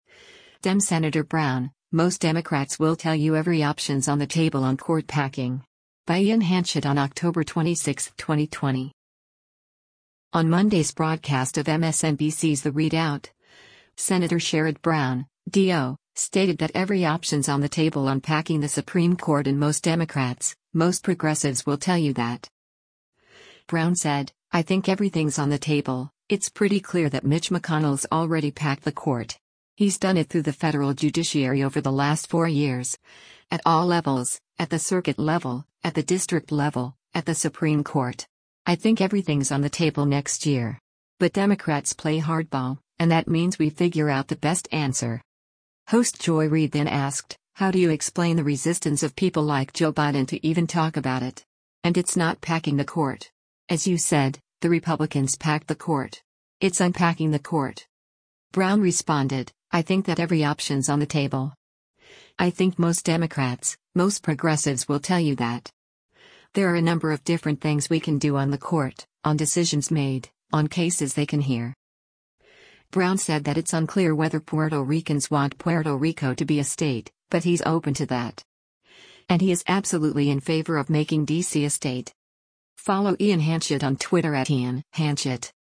On Monday’s broadcast of MSNBC’s “The ReidOut,” Sen. Sherrod Brown (D-OH) stated that “every option’s on the table” on packing the Supreme Court and “most Democrats, most progressives will tell you that.”